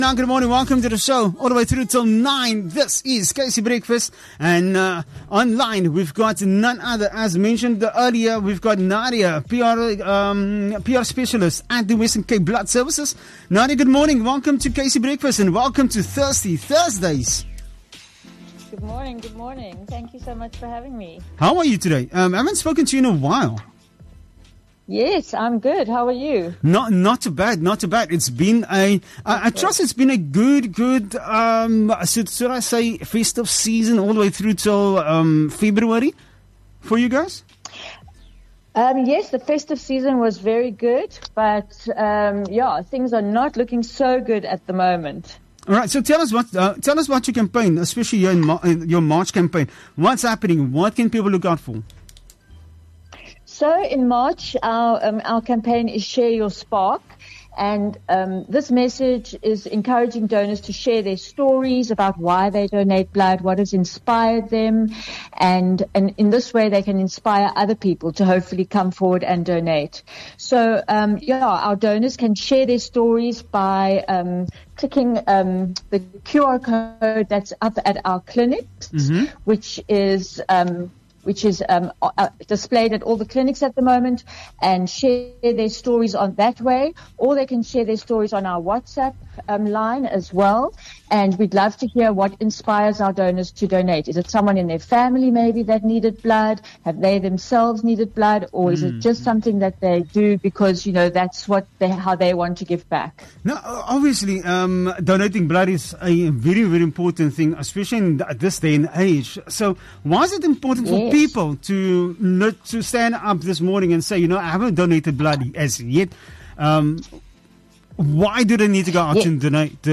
19 Mar WCBS - Marketing Interview